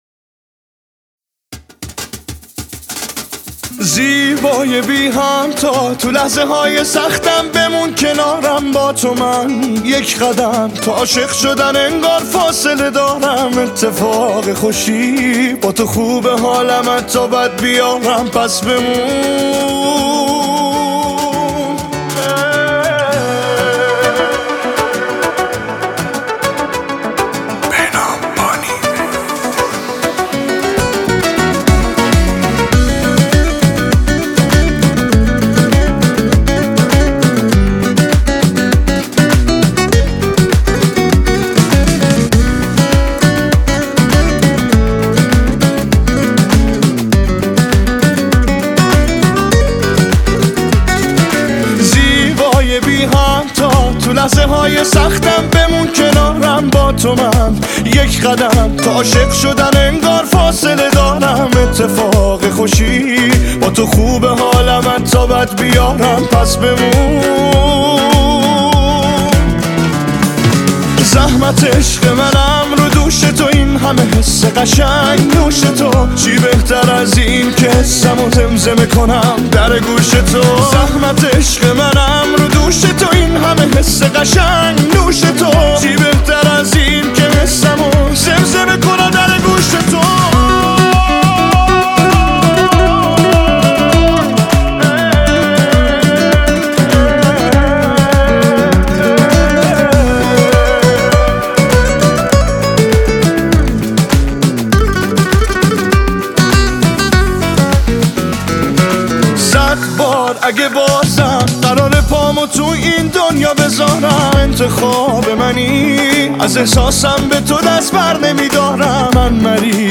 اهنگ زیبا و احساسی